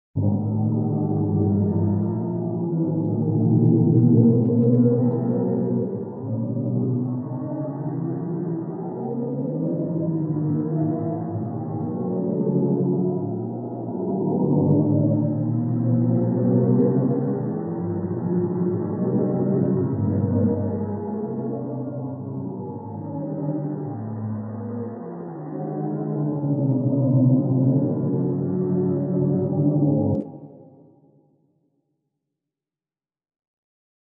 NASA Captura por Primera Vez el Sonido de un Agujero Negro ¡Escuchalo!
Estas ondas, que originalmente no eran perceptibles para el oído humano, han sido traducidas a sonido mediante una técnica conocida como ‘sonificación’.
Para hacer posible que estas ondas se volvieran audibles, la NASA extrajo y resintetizó las ondas de sonido previamente identificadas, ajustando su frecuencia para que entraran en el espectro audible para el oído humano.